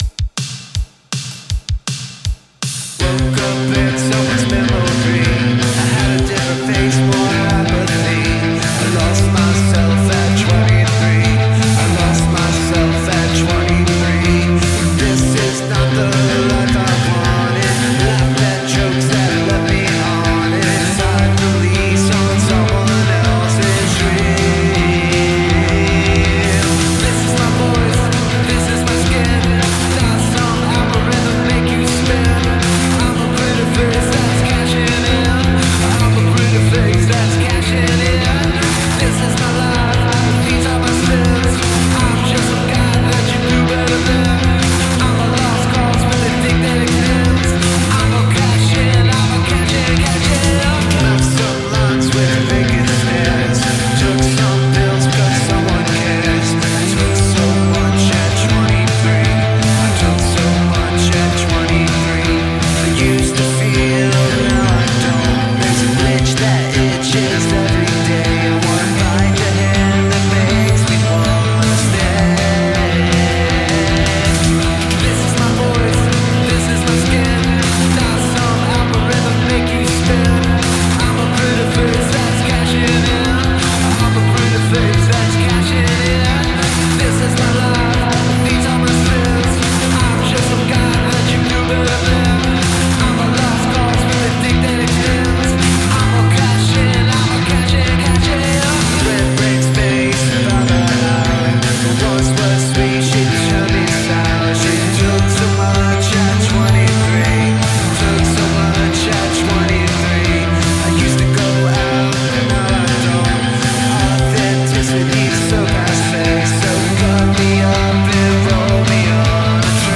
Gothic Rock, Dreampop, Alternative